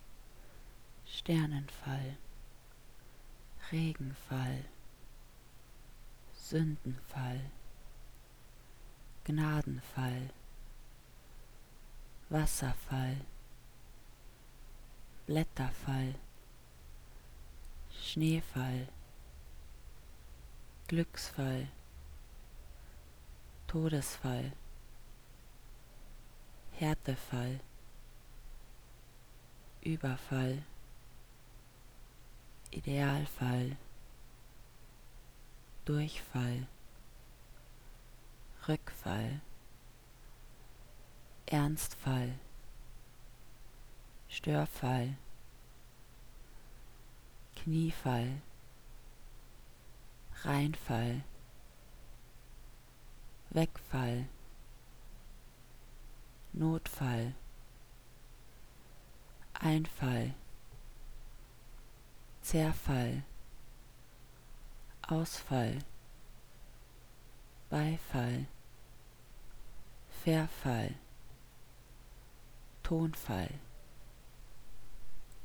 Die gebetsmühlenartige Wiederholung der gleichen Wortendungen greift den Herzrhythmus auf.
As in music, the eardrum begins to oscillate in time to the beat of the repeated words – a drummer in the ear, so to speak.
The sound of cascading words with identical endings can put a sensitive audience into a kind of trance.